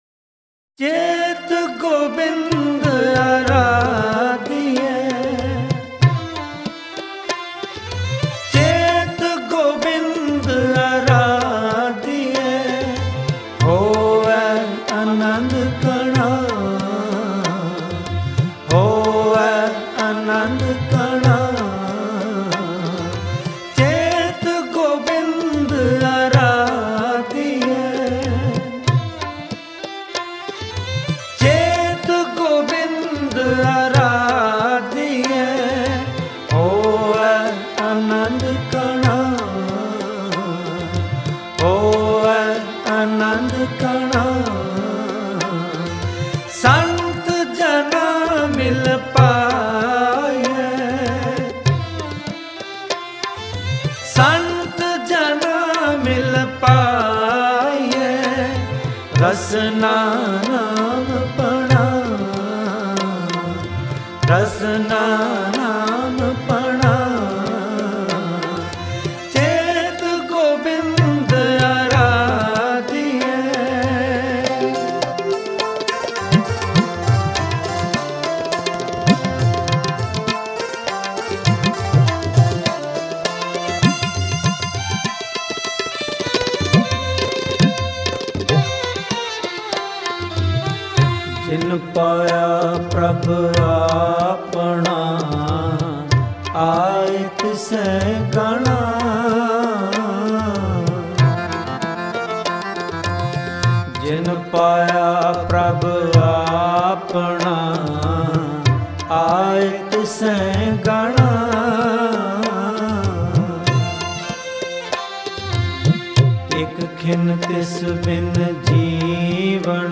& Save target as   Shabad sang by
Bhai Harjinder Singh